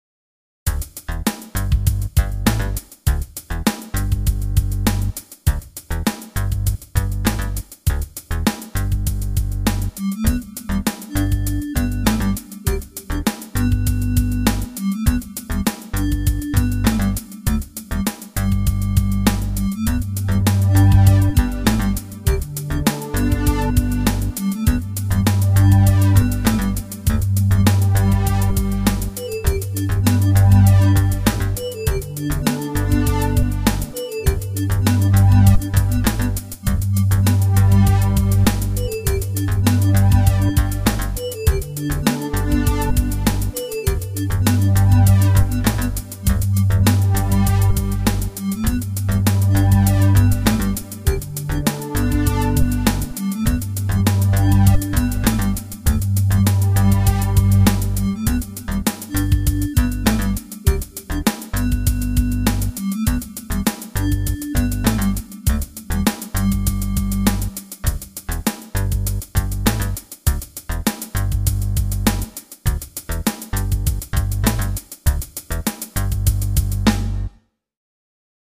Catchy, electronic, instrumental pop.